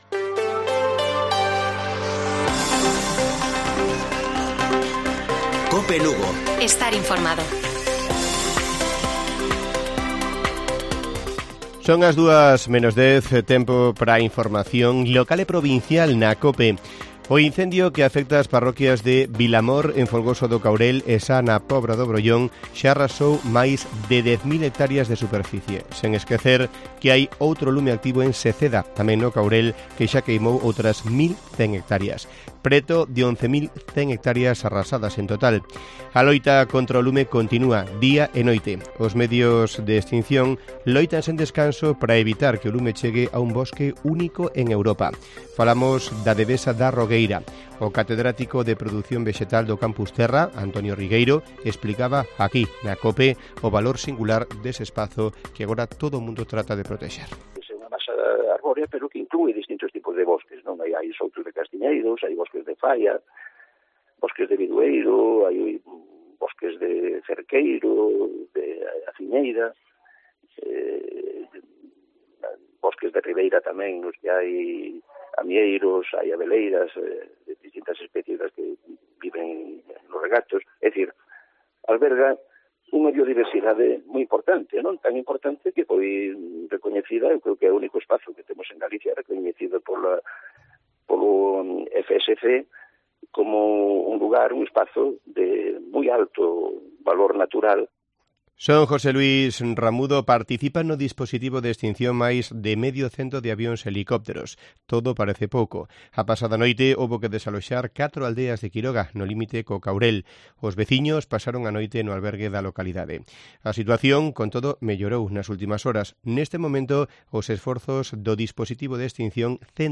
Informativo Mediodía de Cope Lugo. 21 DE JULIO. 13:50 horas